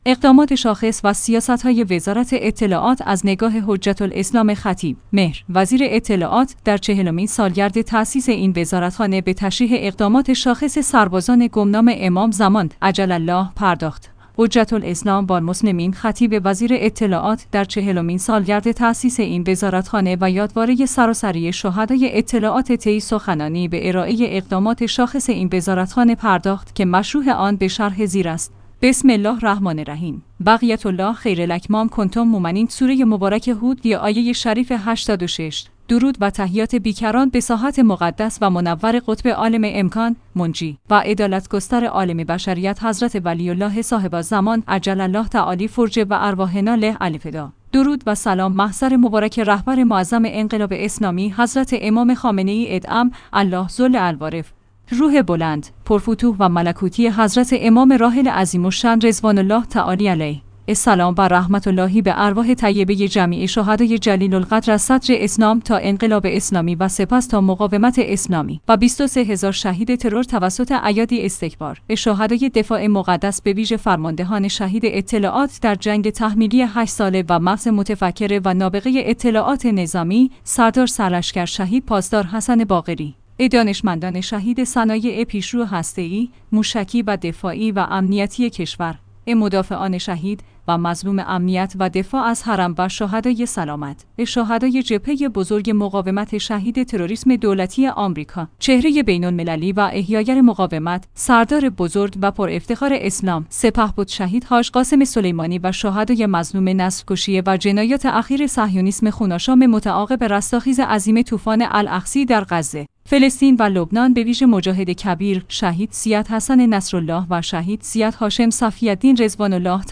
حجت الاسلام والمسلمین خطیب وزیر اطلاعات در چهلمین سالگرد تأسیس این وزارتخانه و یادواره سراسری شهدای اطلاعات طی سخنانی به ارائه اقدامات شاخص این وزارتخانه پرداخت که مشروح آن به شرح زیر است